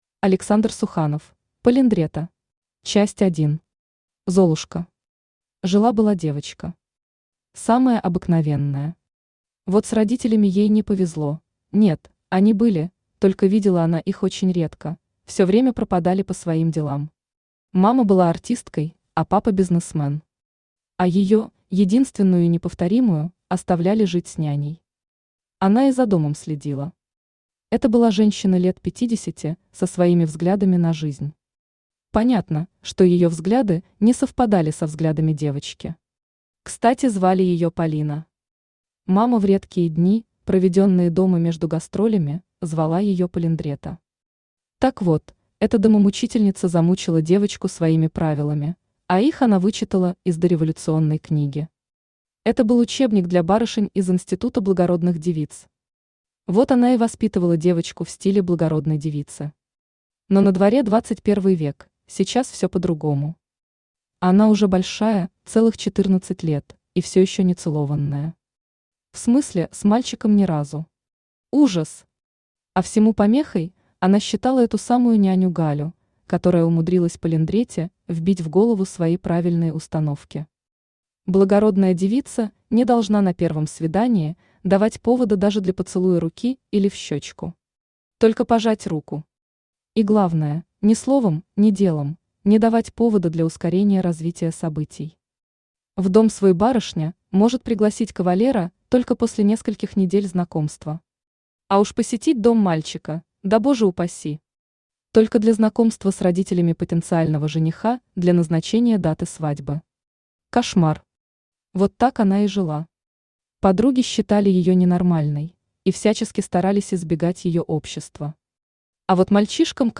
Аудиокнига Полиндретта | Библиотека аудиокниг
Aудиокнига Полиндретта Автор Александр Суханов Читает аудиокнигу Авточтец ЛитРес.